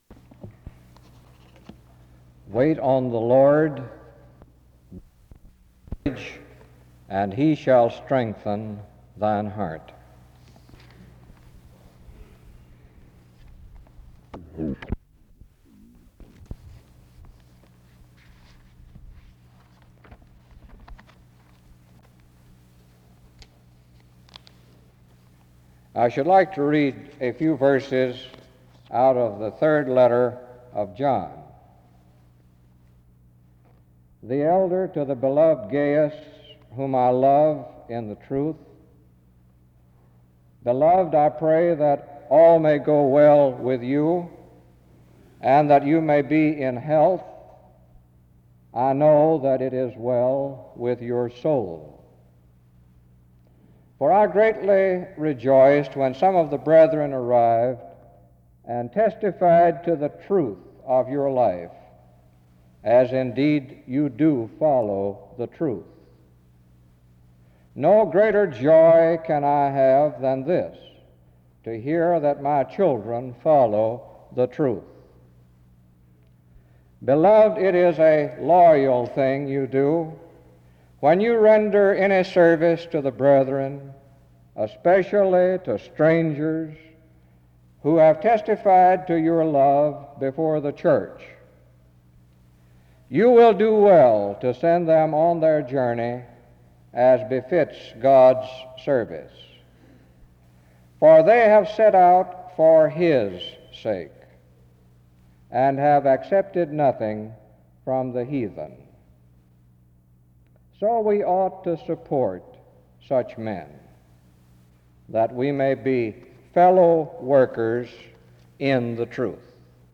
The service begins with the reading of Psalm 27:14 (00:00-00:10) and 3 John 1:1-8 (00:11-02:01).
SEBTS Chapel and Special Event Recordings SEBTS Chapel and Special Event Recordings